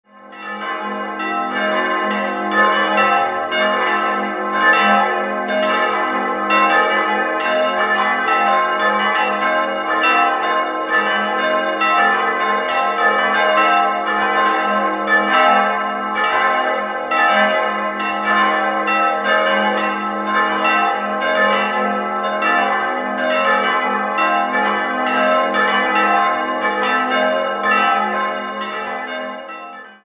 Das Langhaus wurde im Jahr 1902 verlängert. 4-stimmiges Geläute: g'-a'-c''-e'' Die drei kleineren Eisenhartgussglocken wurden 1921 von Ulrich&Weule gegossen, die große Bronzeglocke stammt von Eduard Becker und wurde 1871 in Ingolstadt gegossen.